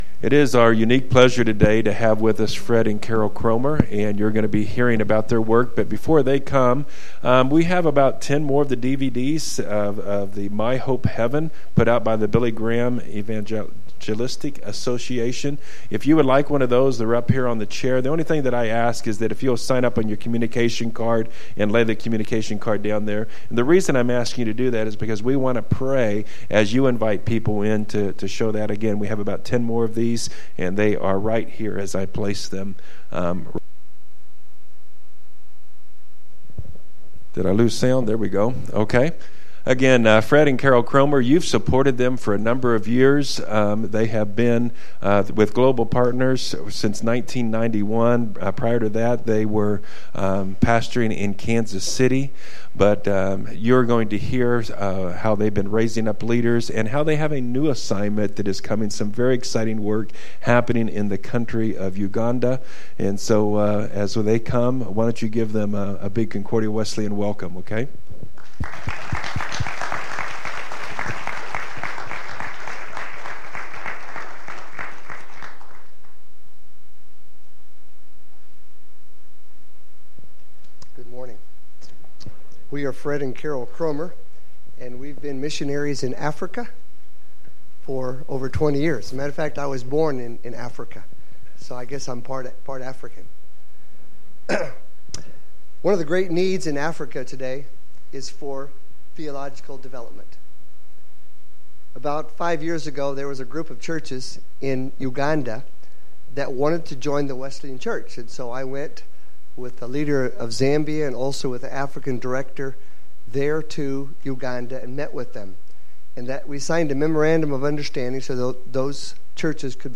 Concordia Wesleyan Church: 2014 Sermons